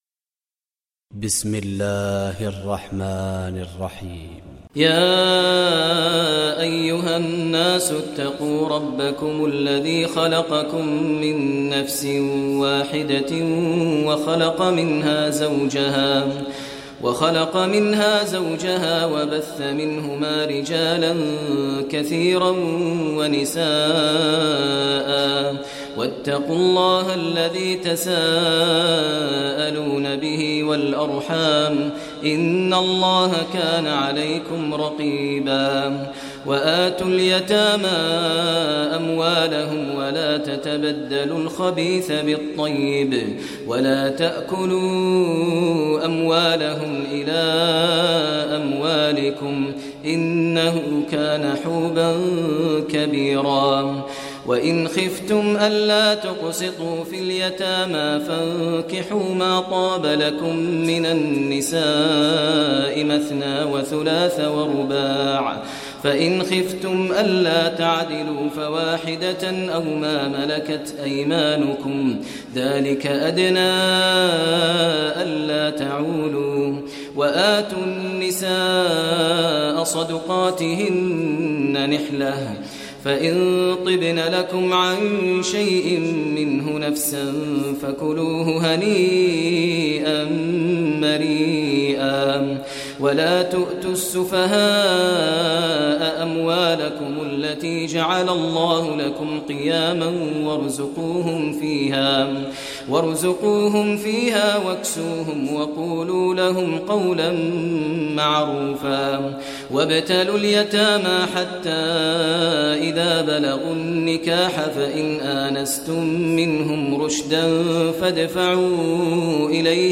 Surah Nisa Recitation by Maher al Mueaqly
Surah Nisa, listen online mp3 tilawat / recitation in Arabic in the voice of Sheikh Maher al Mueaqly.